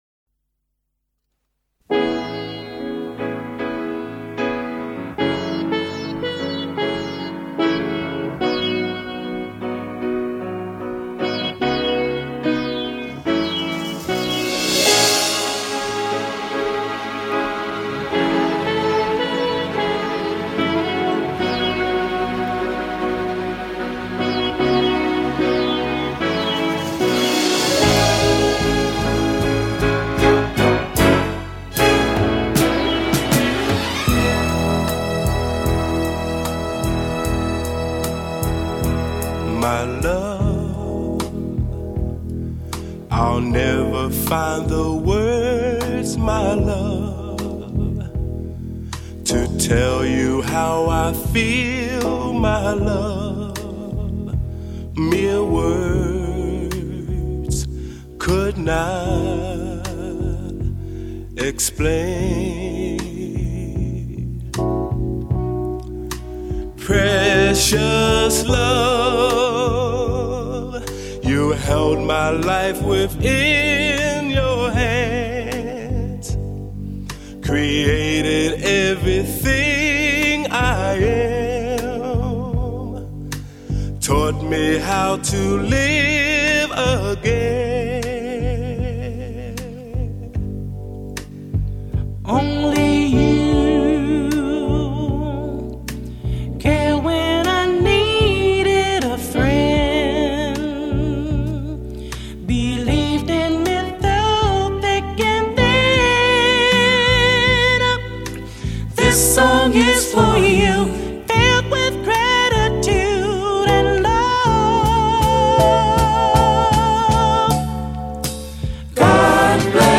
Dit is mijn muziek niet, veel te langzaam.